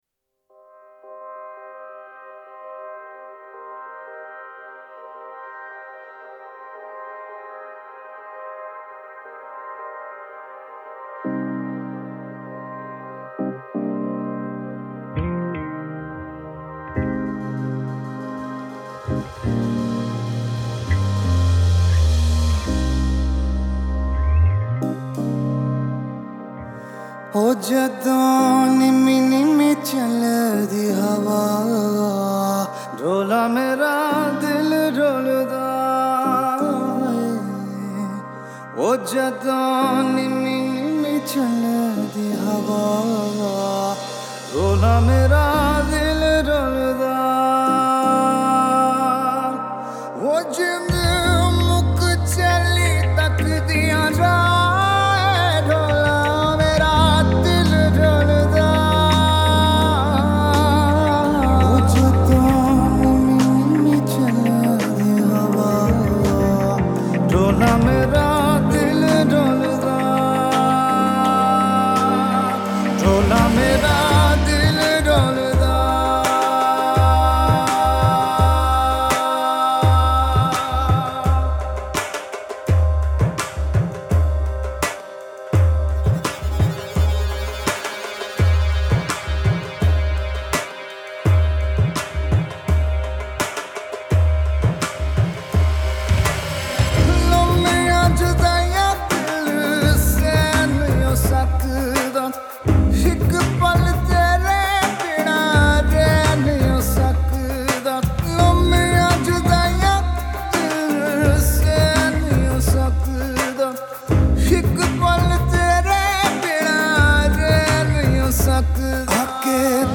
Vocals & Drums
Keyboards
Dhol
Guitar
Bass